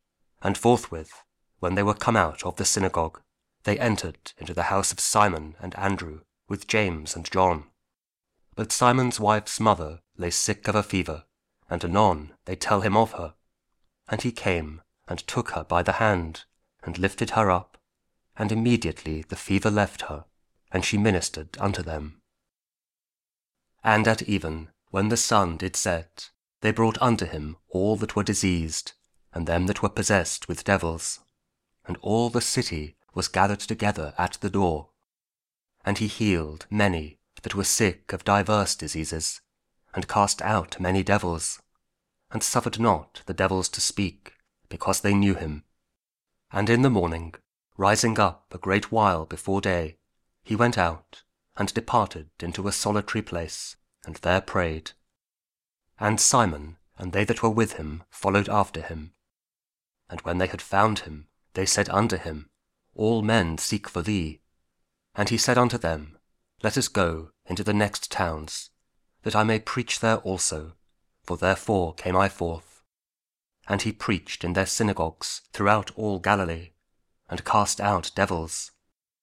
Mark 1: 29-39 – Week 1 Ordinary Time, Wednesday (Audio Bible KJV, Spoken Word)